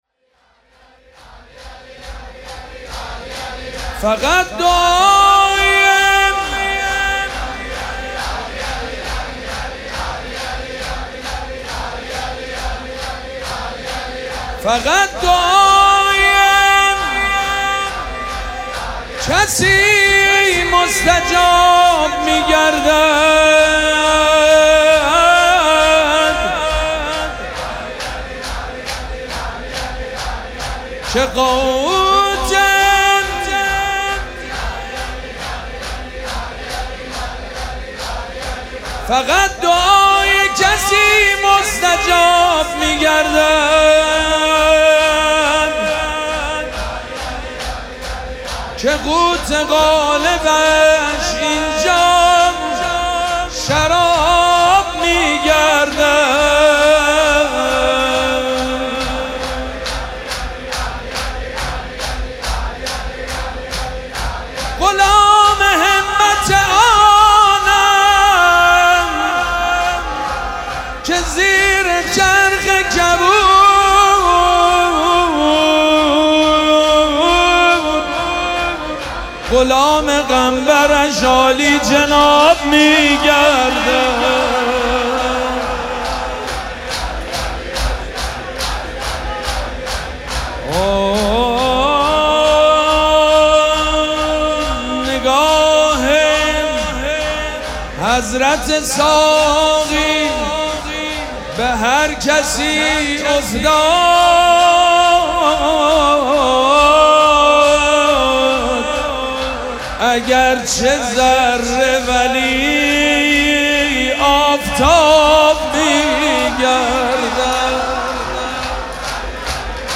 فقط دعای کسی مستجاب می شود - نغمه خوانی شب چهارم فاطمیه 1403